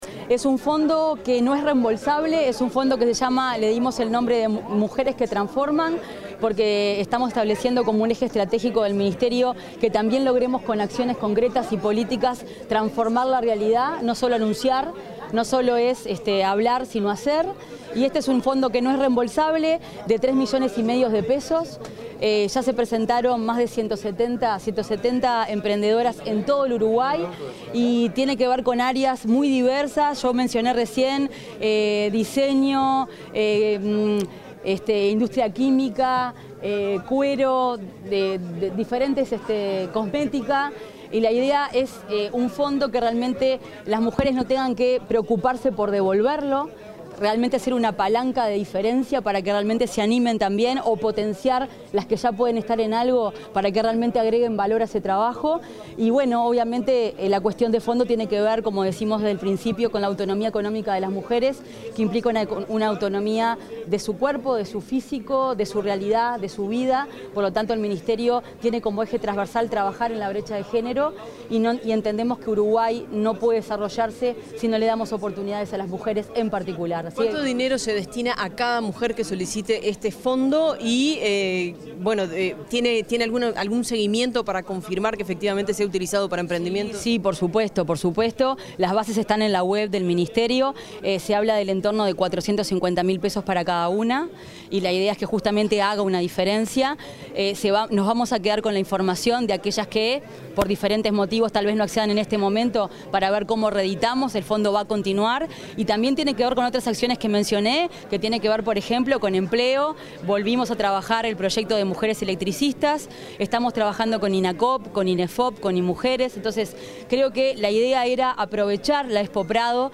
Declaraciones de la ministra de Industria, Energía y Minería, Fernanda Cardona